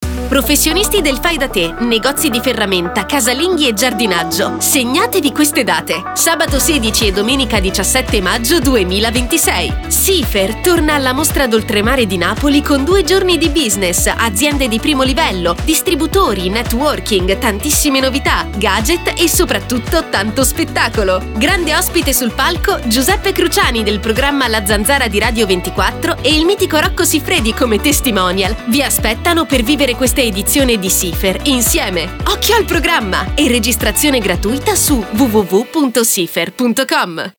Ascolta lo spot del SiFerr 2024